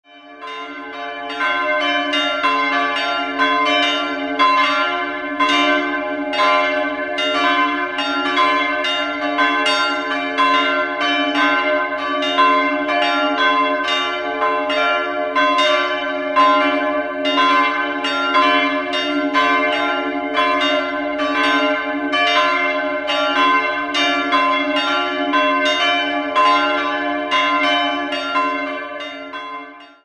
3-stimmiges Gloria-Geläute: c''-d''-f'' Die mittlere Glocke d'' (232 kg) goss Friedrich Wilhelm Schilling im Jahr 1953.